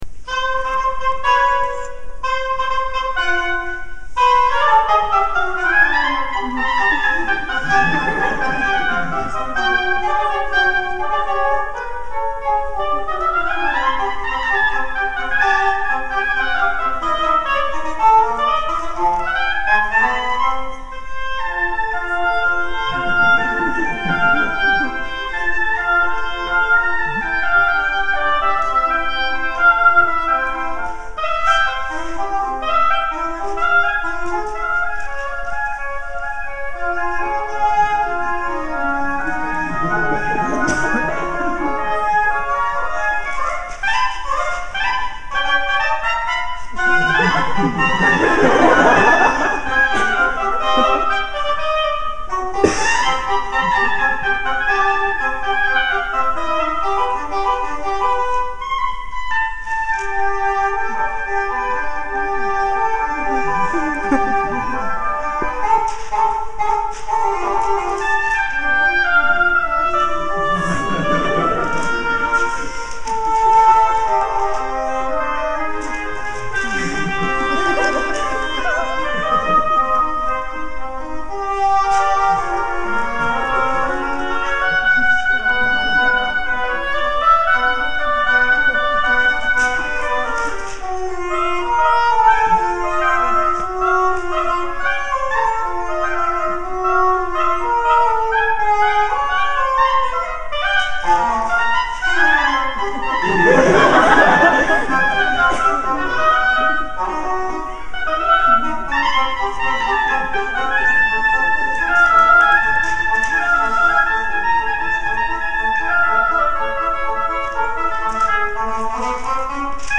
Quodlibet
Quodlibet 1544 (Renaissance+) 7 works by 7 composers Vocal [57%] Orchestral [43%] Group: Instrumental Synonyms: Кводлибет A piece of music combining several different melodies, usually popular tunes, in counterpoint and often a light-hearted, humorous manner. Quodlibet for Oboe and English Horn
Quodlibet for Oboe and English Horn.mp3